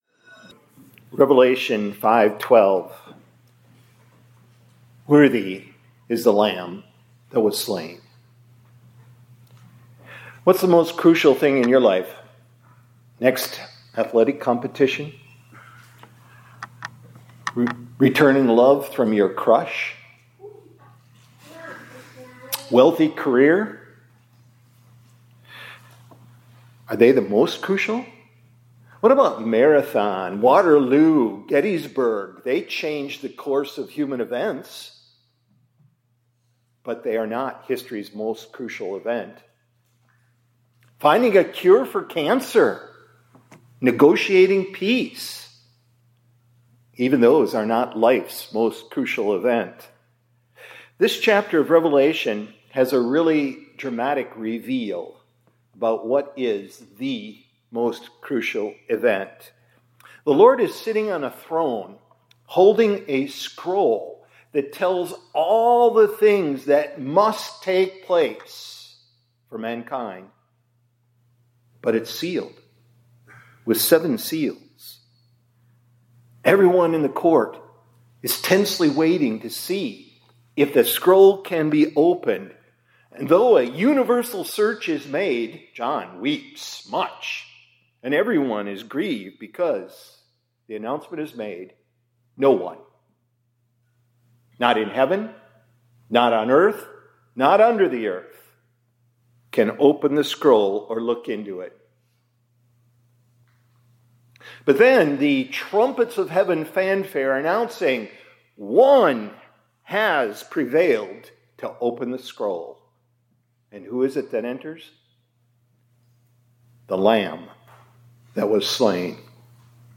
2024-04-22 ILC Chapel — Out Of Sight, Out Of…